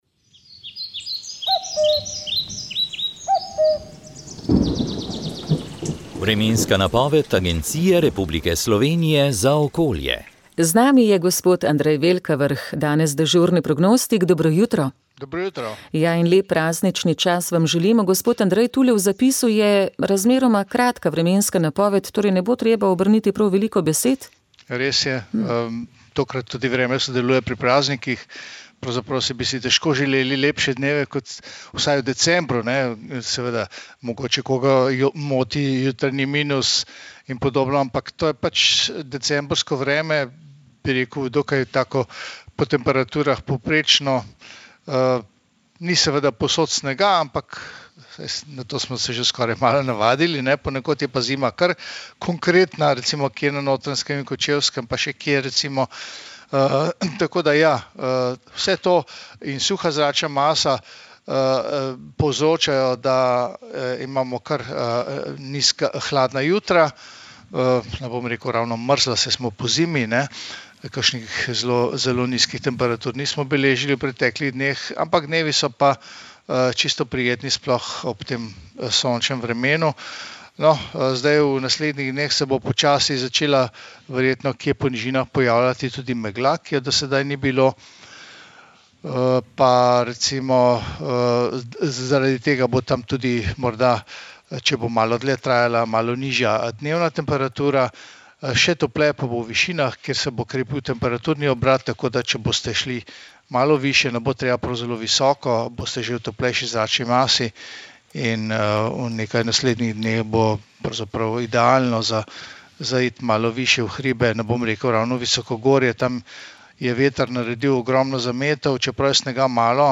Vremenska napoved 25. december 2024